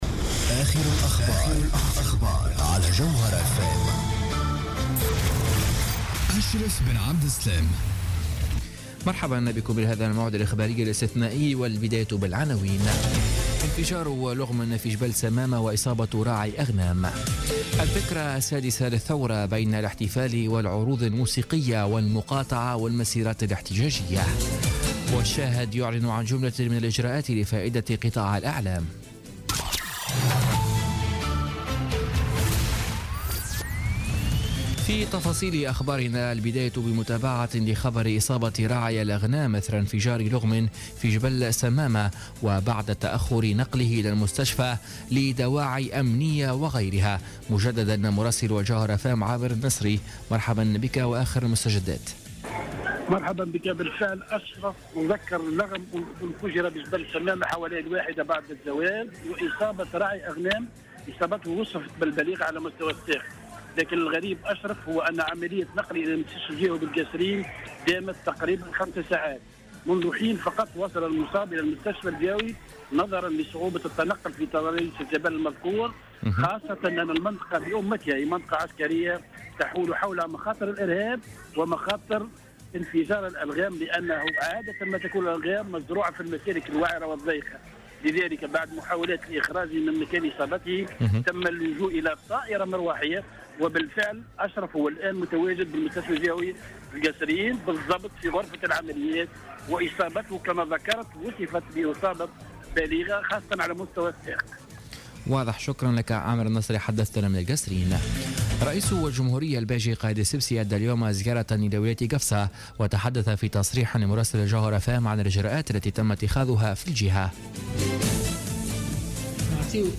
نشرة أخبار السابعة مساء ليوم السبت 14 جانفي 2017